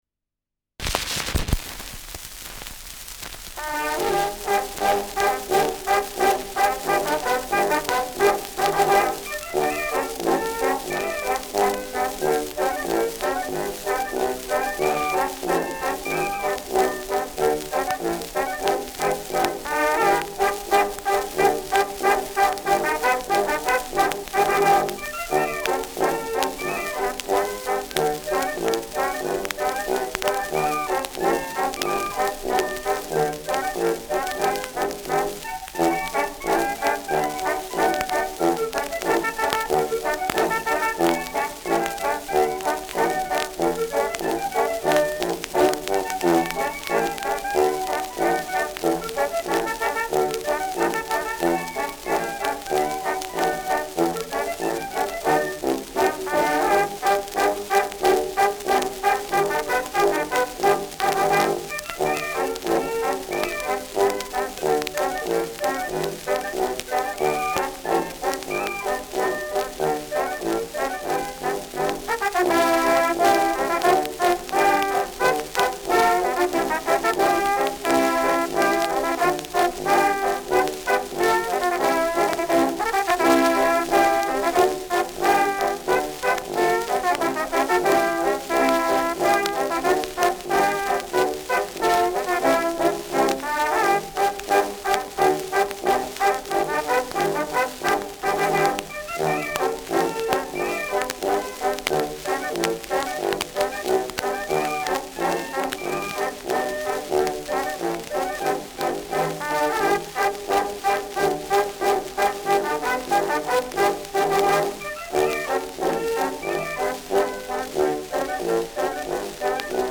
Schellackplatte
[unbekanntes Ensemble] (Interpretation)